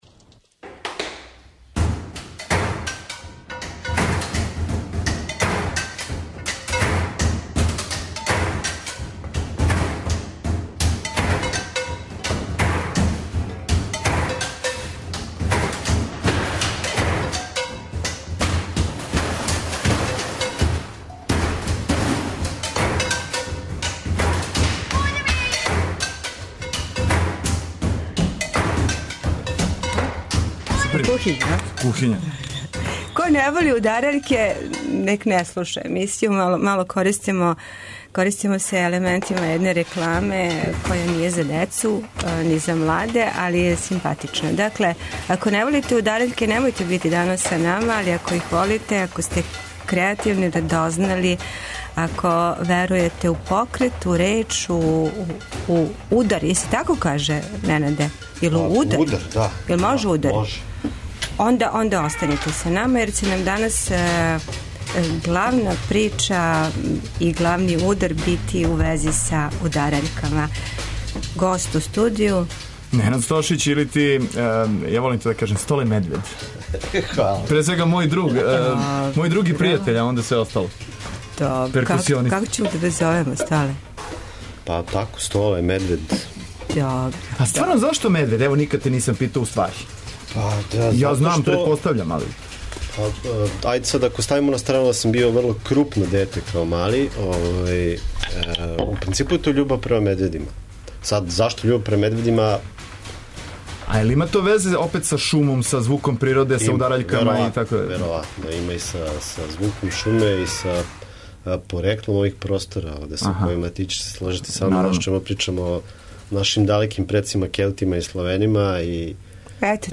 А, све то на трагу онога што ће се догађати на овогодишњем БЕЛЕФ-у. (Ако не волите удараљке, ова емисија није за вас).